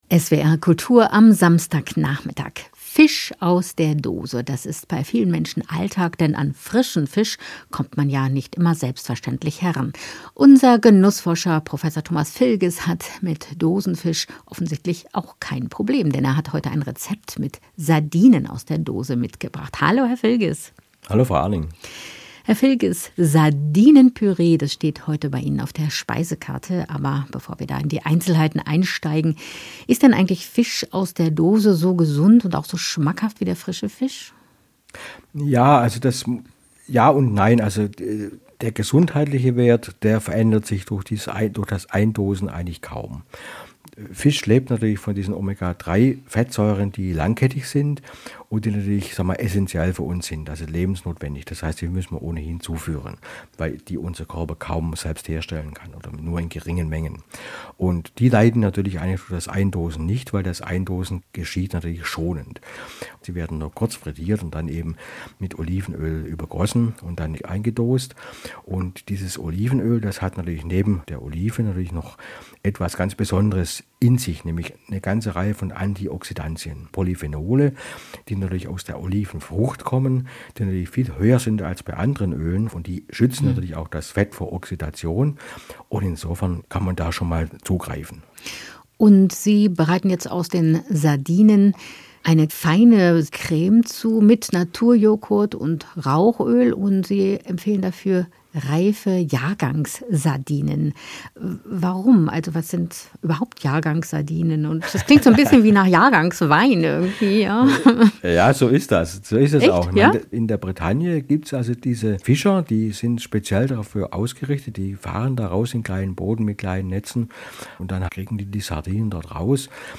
Das Gespräch führte
Gespräch mit